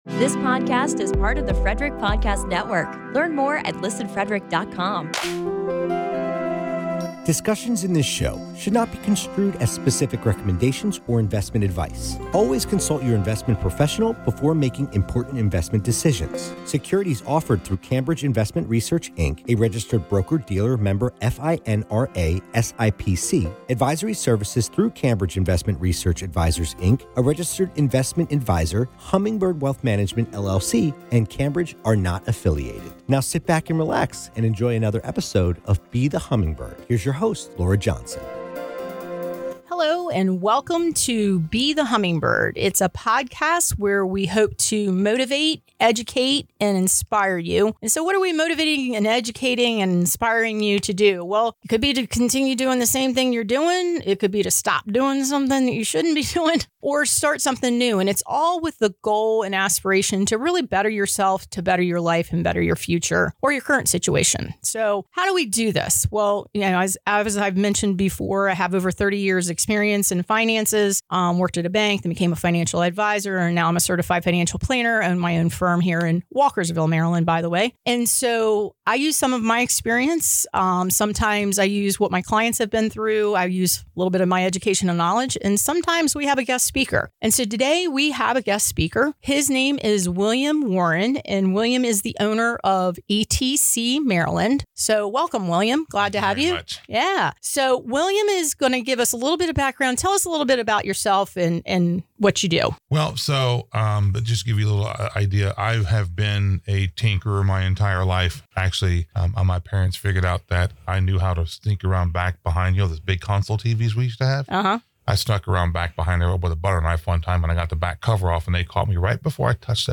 I had the pleasure of being a guest speaker on the Be The Hummingbird podcast.